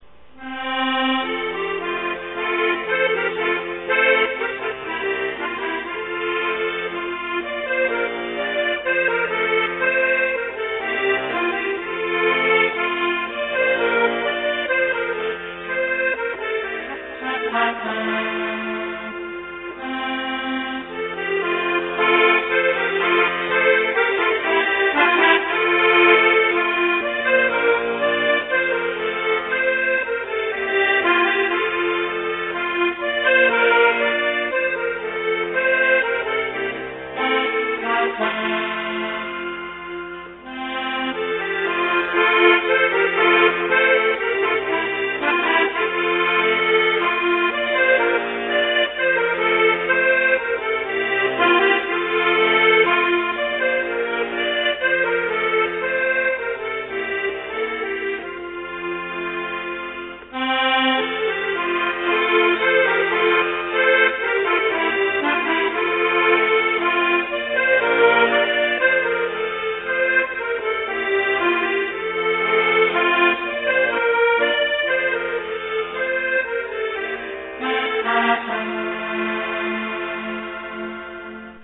German Folksongs